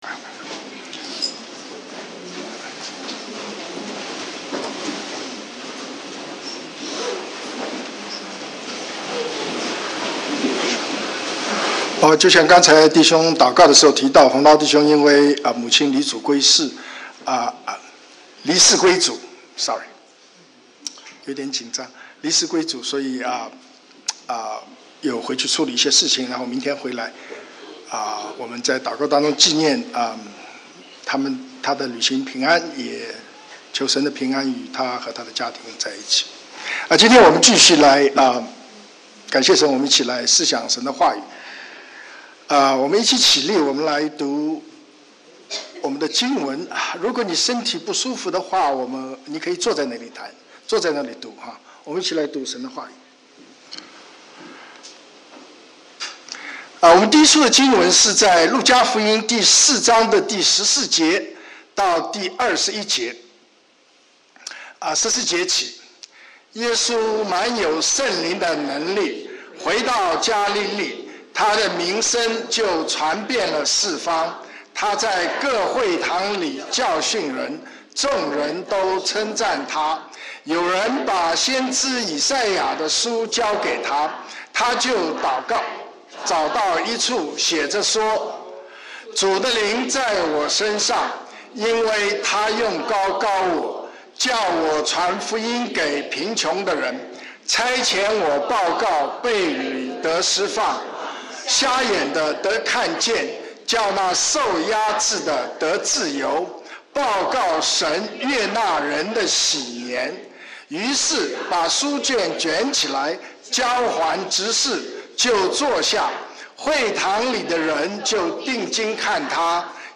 Sunday Sermons (Chinese)